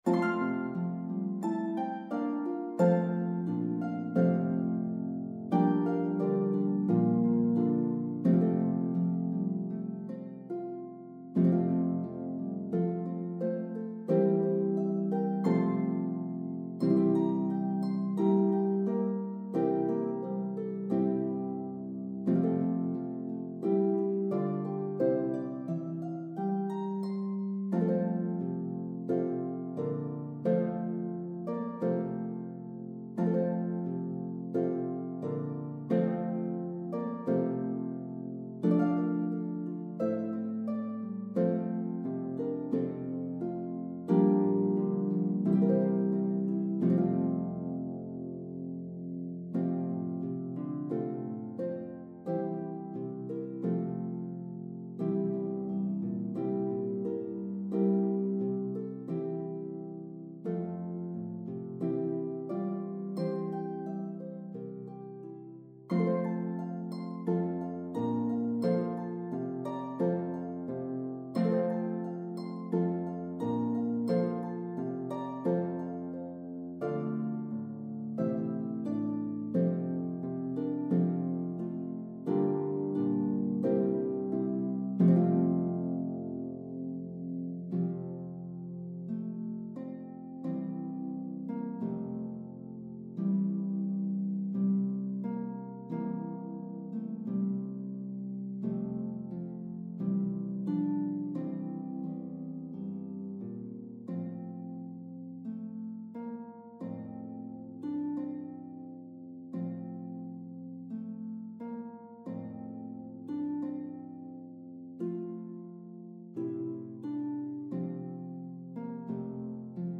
hymn setting
This arrangement includes all four verses.